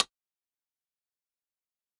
Perc [MPC Rim].wav